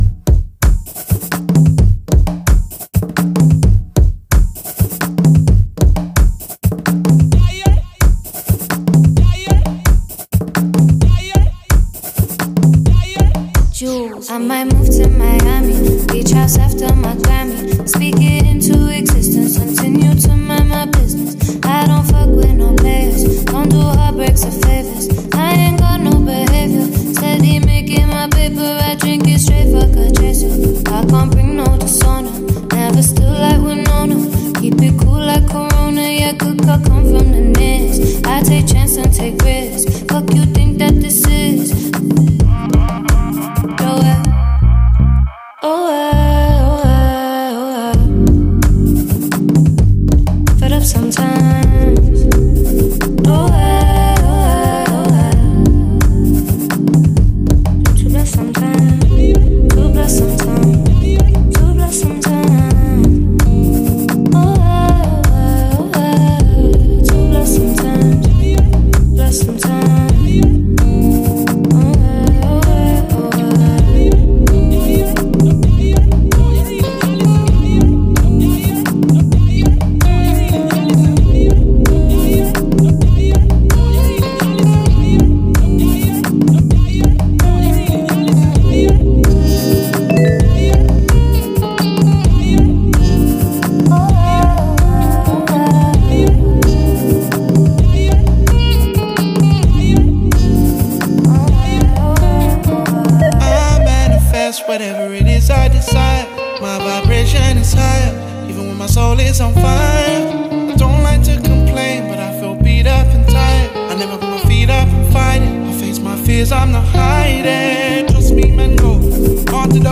catchy new song